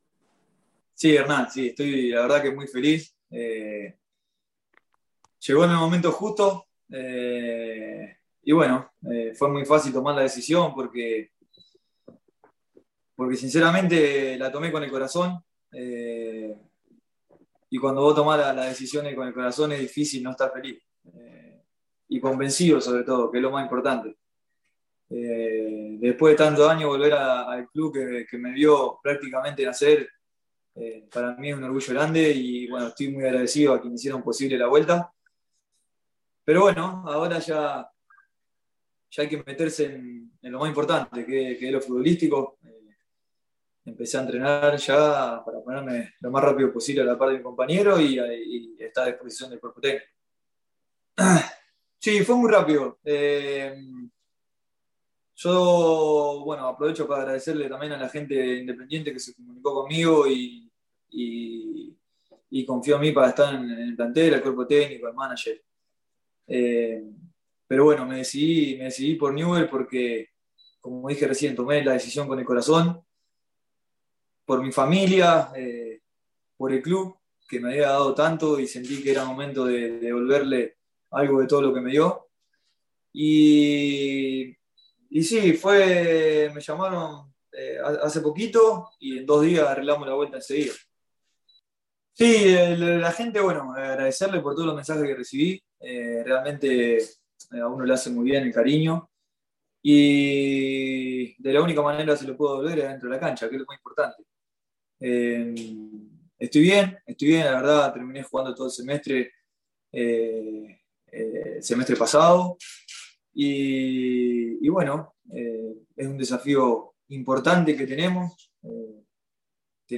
El segundo refuerzo de la lepra, Leonel Vangioni, habló en conferencia de prensa de cara a su segunda etapa como jugador del club. Se expresó sobre como fue la negociación para su vuelta, como ve al equipo y de que posición podría jugar en la formación de Javier Sanguinetti.